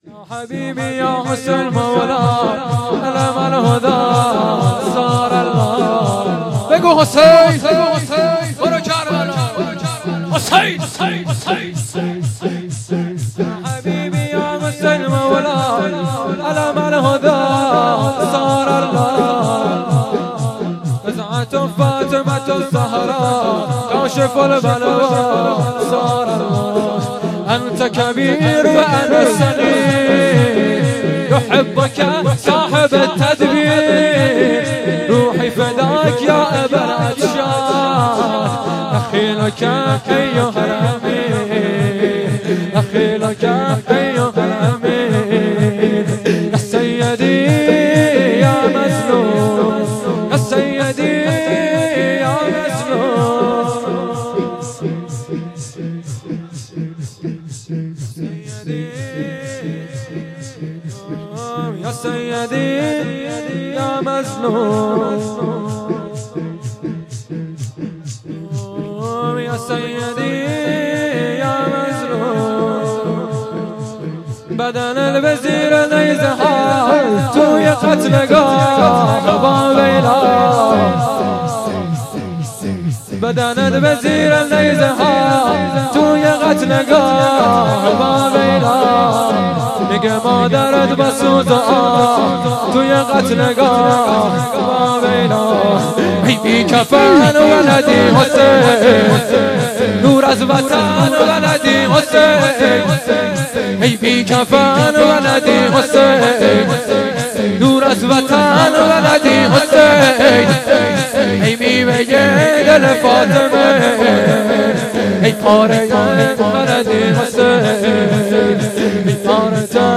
شهادت حضرت باقرالعلوم(26 مرداد سال97)